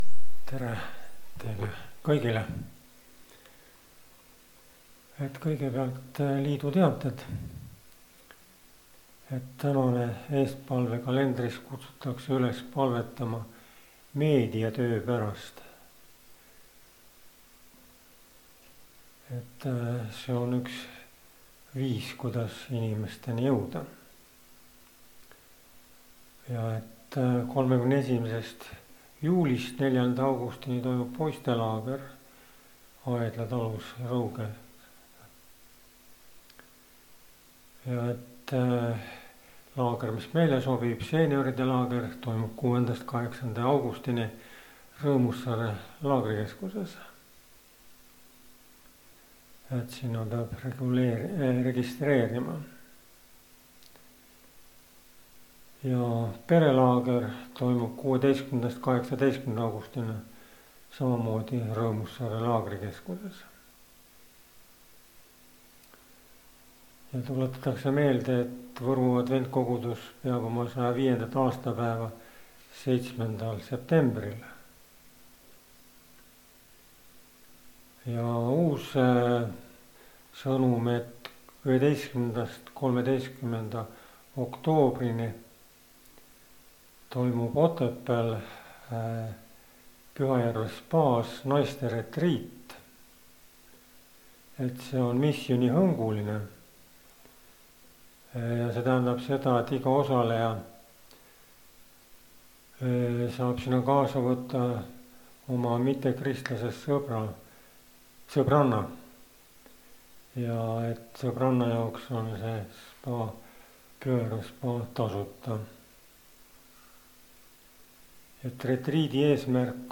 Koosolekute helisalvestused